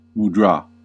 [muːˈdrɑː] (bantuan·info), erti: "lambang" atau "isyarat") adalah gerak isyarat atau sikap tubuh yang bersifat simbolik atau ritual dalam Hinduisme dan Buddhisme.[1] Ada beberapa mudrā yang melibatkan seluruh anggota tubuh, akan tetapi kebanyakan hanya dilakukan dengan tangan dan jari.
En-us-mudra-2.ogg